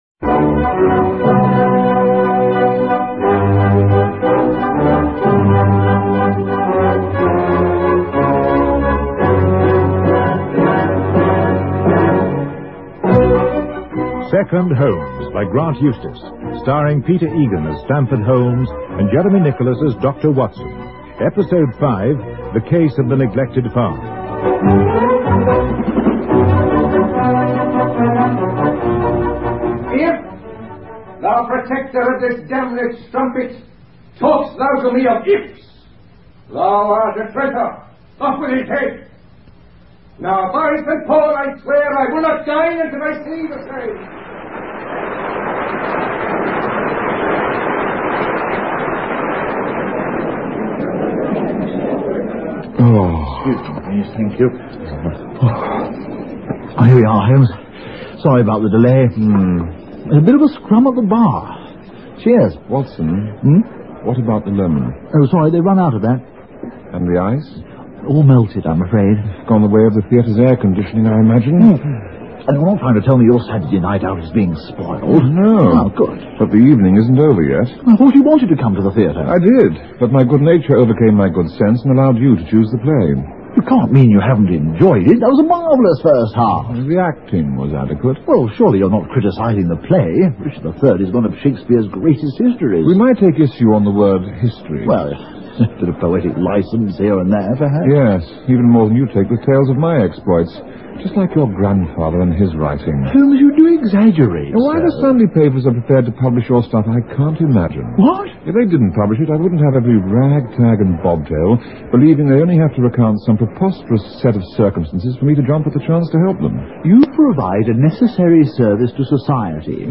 Radio Show Drama with Sherlock Holmes - The Neglected Farm 1983